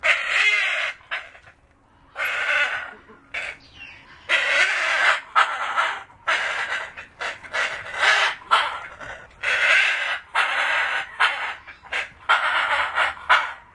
热带鸟类 " fr0608bg 风信子金刚鹦鹉 2
描述：风信子金刚鹦鹉的异国情调的叫声还可以听到它的大翅膀的声音。录制于普罗旺斯的Le Jardin D'Oiseaux Tropicale。
标签： 鸟呼叫 鸟的歌声 现场录音 风信子金刚鹦鹉 丛林 热带鸟
声道立体声